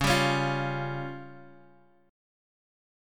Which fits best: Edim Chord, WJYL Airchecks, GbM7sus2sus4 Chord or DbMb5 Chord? DbMb5 Chord